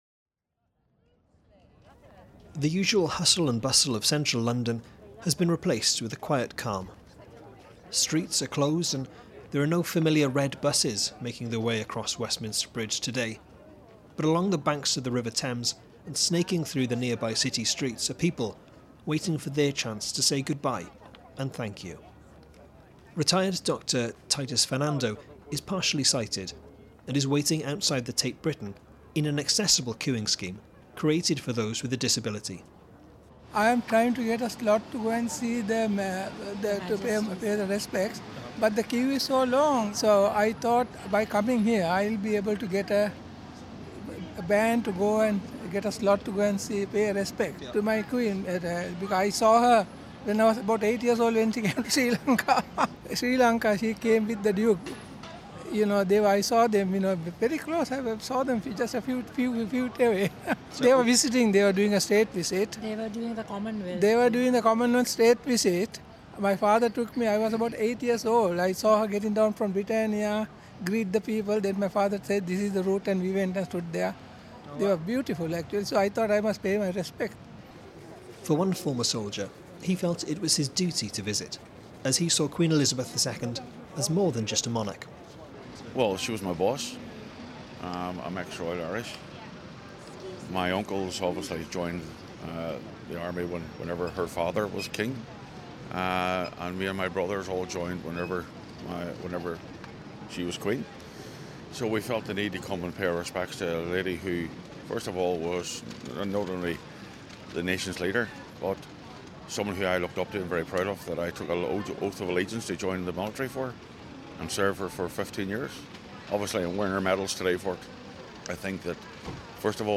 Her Majesty Queen Elizabeth II will lie in state until her funeral on Monday. Tens of thousands of people are lining the streets of London, waiting to pay their respects.